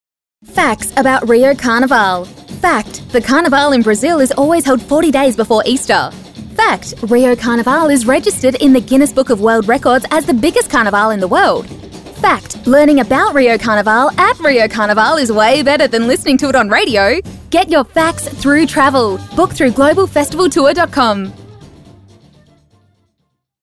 Female
English (Australian)
Yng Adult (18-29), Adult (30-50)
My trained voice is very versatile and can be described in many ways including 'casual and conversational', 'effervescent and warm', 'professional and authentic'.
My audio-enginneered-approved home studio has a Rode NTG2 mic, Focusrite interface and sound-treatment for top-notch audio quality.
Voiceover Reel